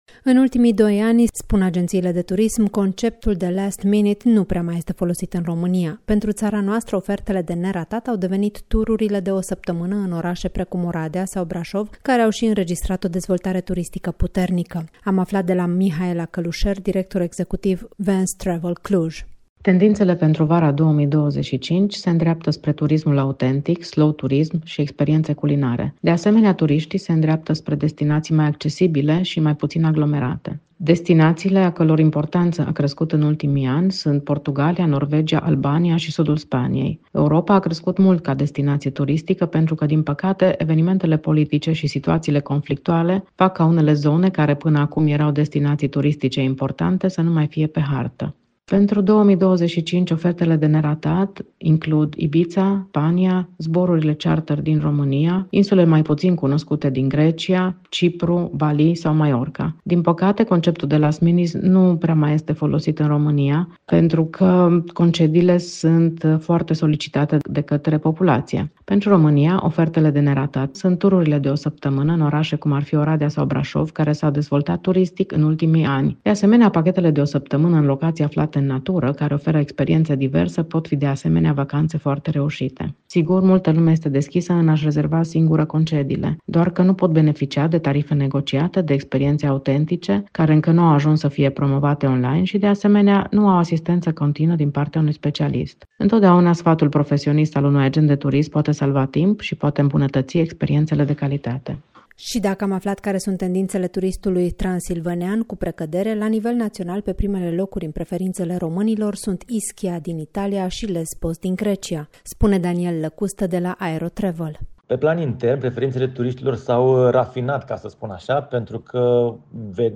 reportaj-concedii.mp3